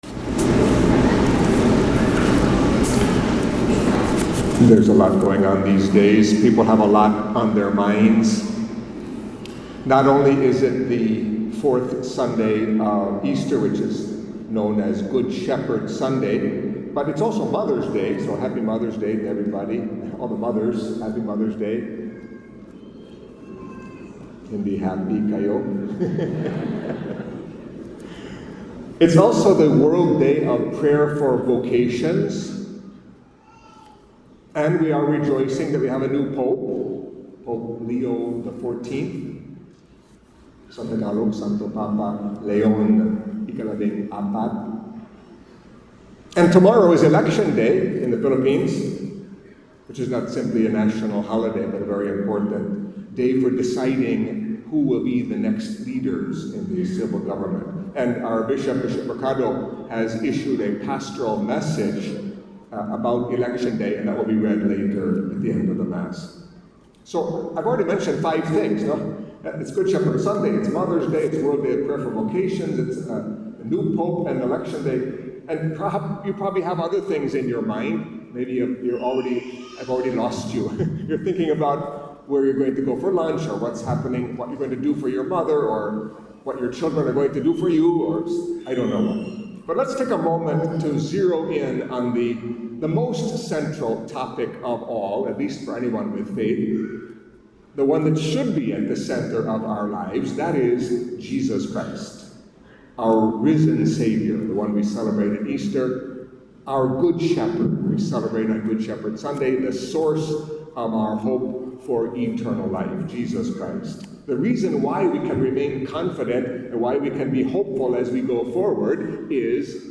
Catholic Mass homily for Fourth Sunday of Easter